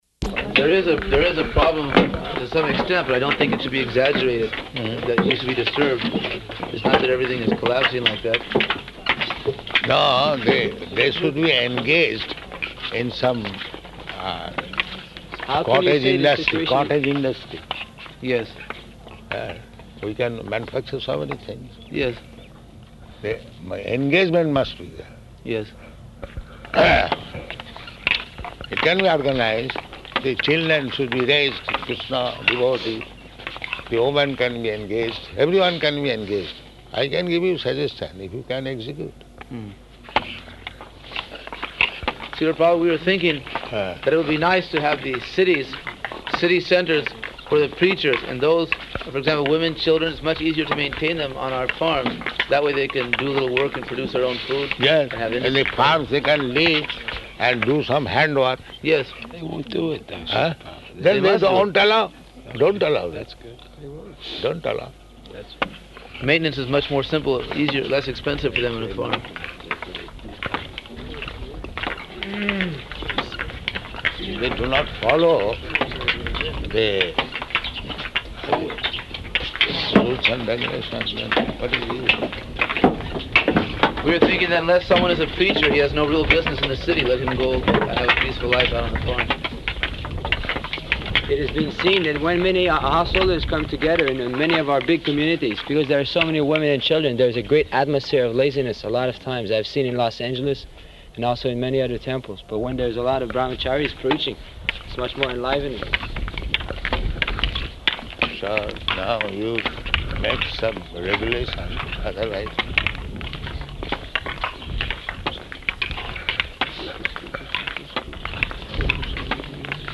Morning Walk [partially recorded]
Type: Walk
Location: Māyāpur